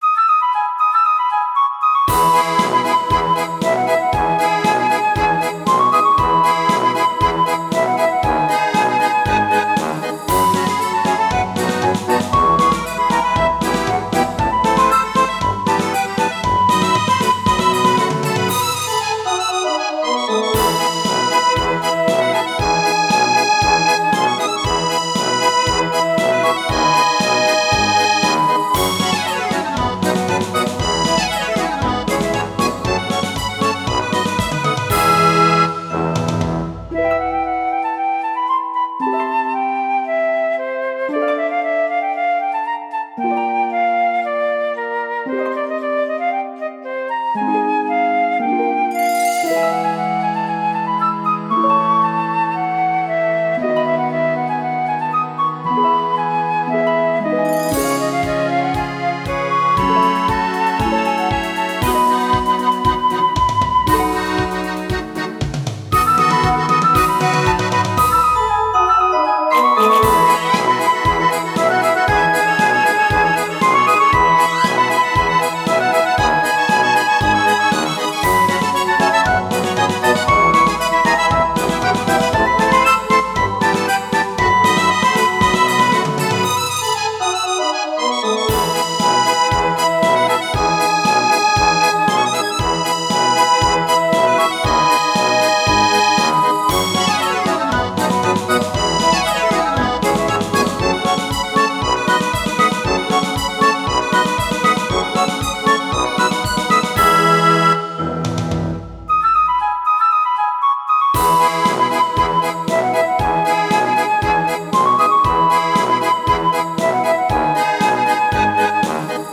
ogg(L) メルヘン 楽しい 妖精楽隊